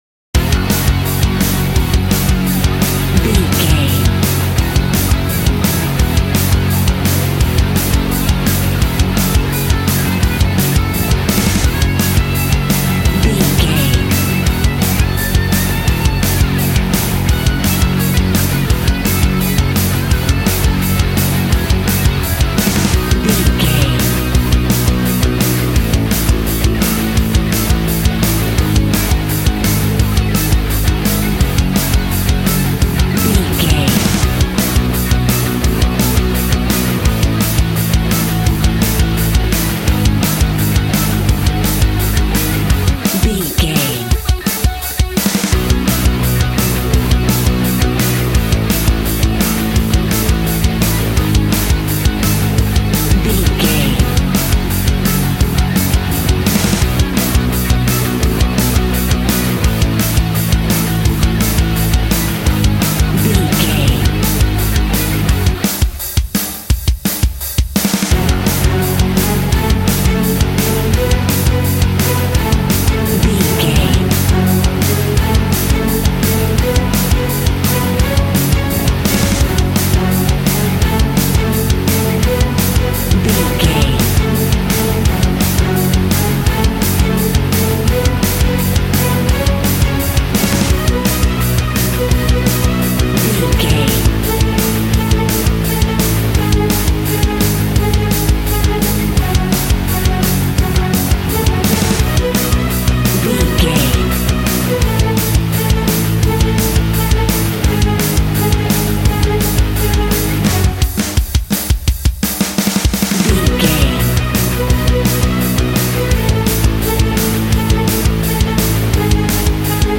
Aeolian/Minor
G♭
angry
aggressive
electric guitar
drums
bass guitar